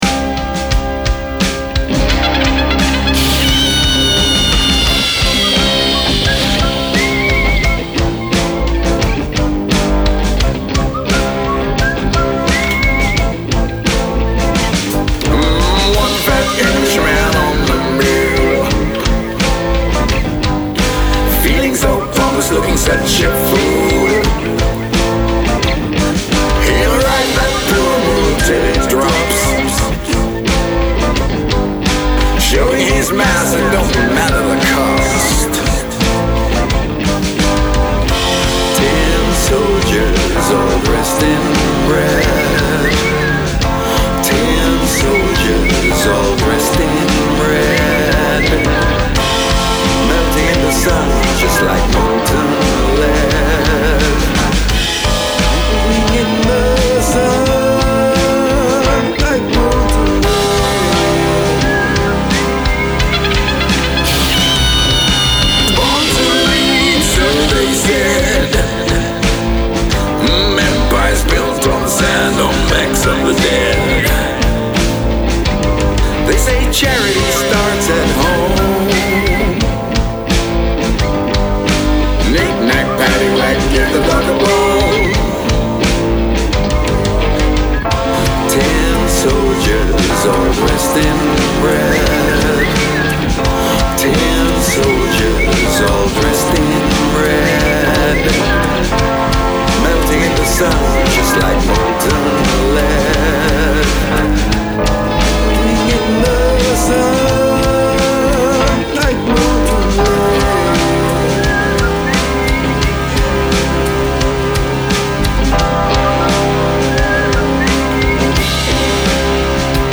jolly foot taping military band sounding song